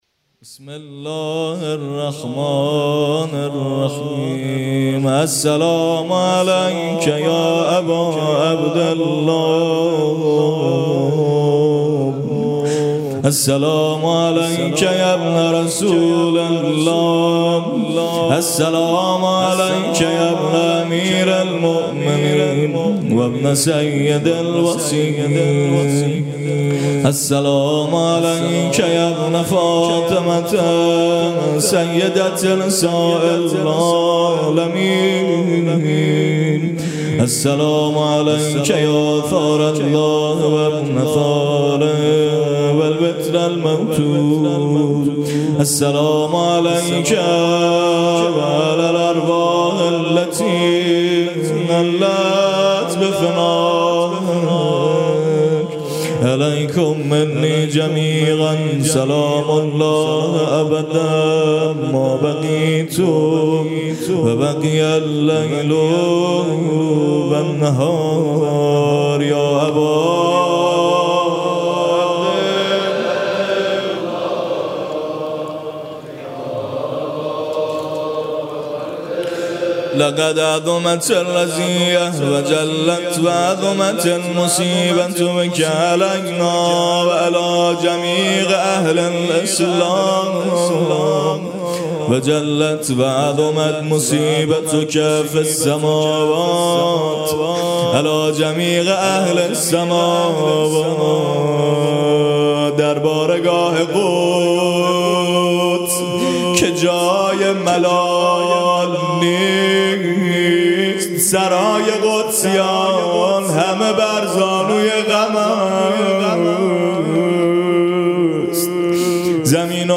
سخنرانی: دین داری فردی لازمه نقش آفرینی اجتماعی